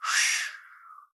sighB.wav